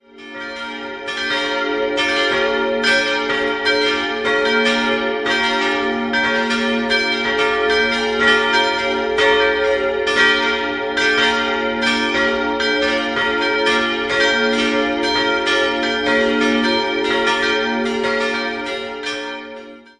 4-stimmiges Salve-Regina-Geläute: b'-d''-f''-g'' Die kleine Glocke wurde von der Gießerei Rüetschi (Aarau) im Jahr 1989 gegossen, die drei größeren stammen aus der Gießerei Keller und entstanden 1872 (Glocke 1), 1874 (Glocke 2) und 1853 (Glocke 3). Es gibt noch eine fünfte, kleine Glocke, die jedoch nicht geläutet werden kann.